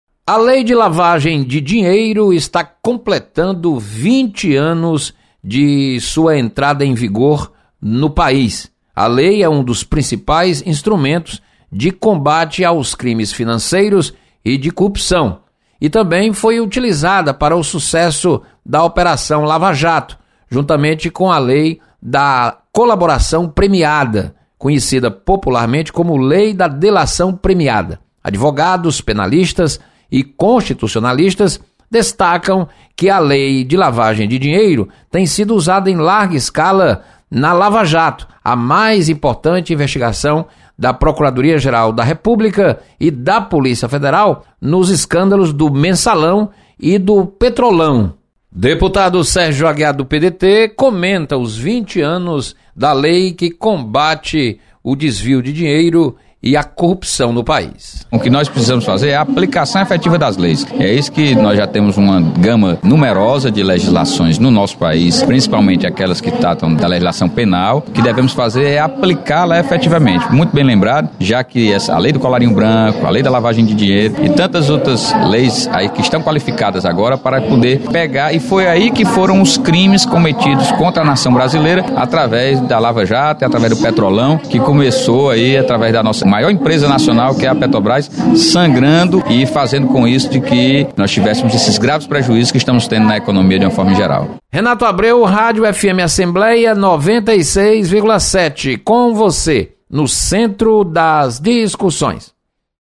Deputado Sérgio Aguiar comenta sobre 20 anos de lei que combate a lavagem de dinheiro.